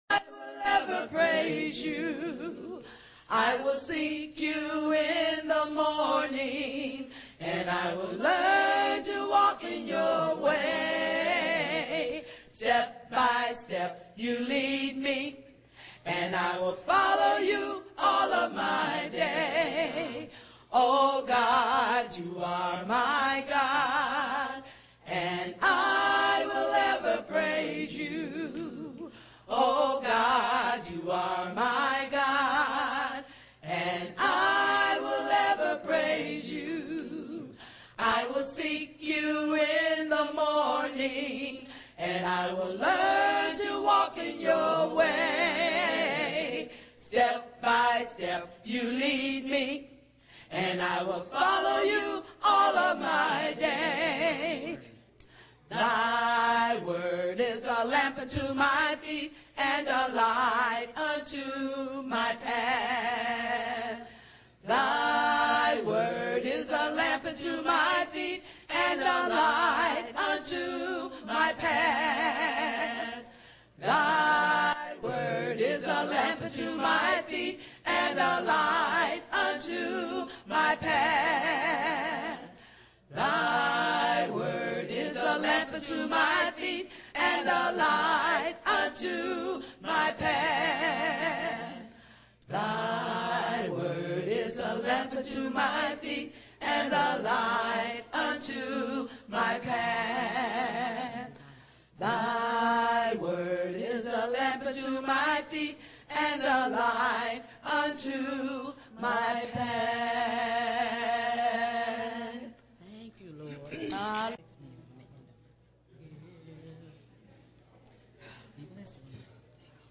Talk Show Episode, Audio Podcast, Ending_Family_Destruction and Courtesy of BBS Radio on , show guests , about , categorized as